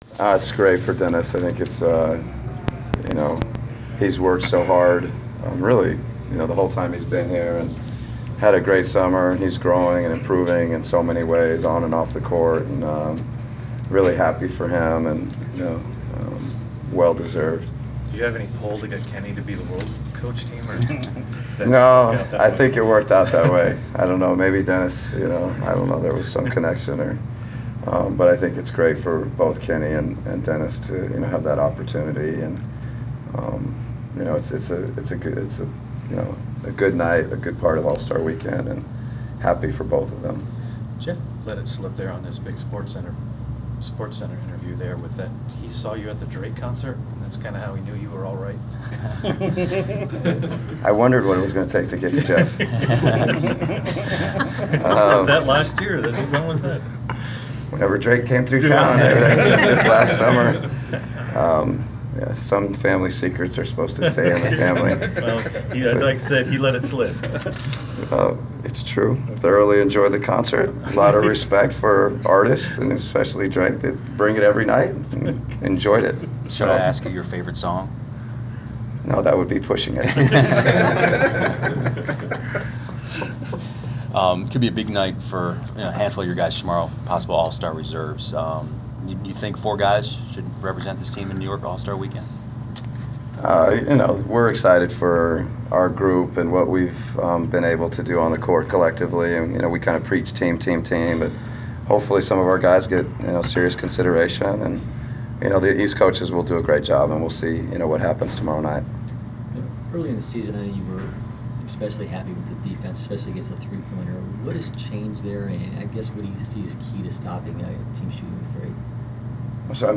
Inside the Inquirer: Pregame presser with Atlanta Hawks’ head coach Mike Budenholzer (1/28/15)
We attended the pregame presser of Atlanta Hawks’ head coach Mike Budenholzer before his team’s home contest against the Brooklyn Nets on Jan. 28. Topics included Dennis Schroder being named to participate in BBVA Compass Rising Stars Challenge, how many Hawks should make the All-Star game and attending a Drake concert.